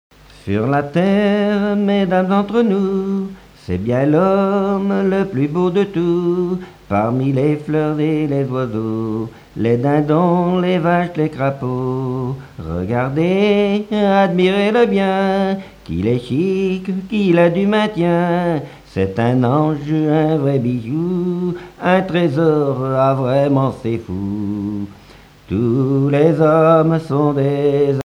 Genre strophique
Chansons populaires et traditionnelles
Pièce musicale inédite